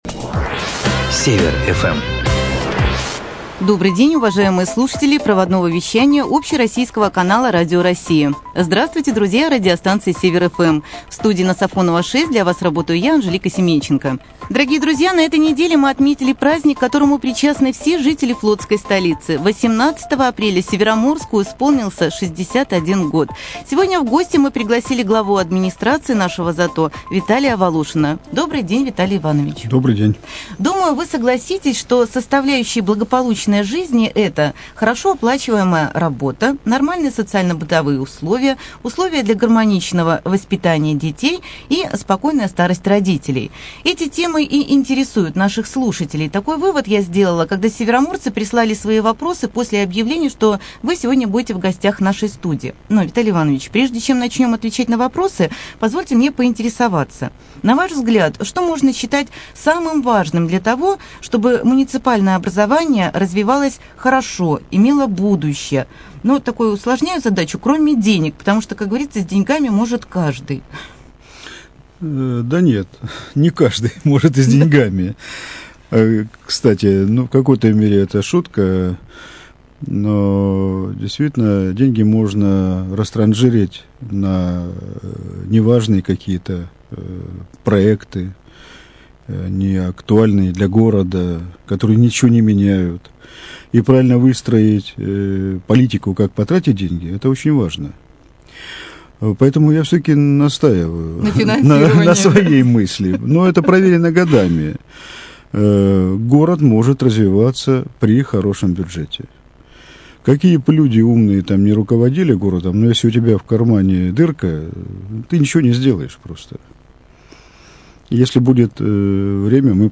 Актуальное интервью
20 апреля Глава администрации муниципального образования ЗАТО г.Североморск В.И.Волошин был гостем редакции североморского радио. В прямом эфире проводного радио и студии Север-FM он ответил на вопросы, которые задали слушатели, жители флотской столицы.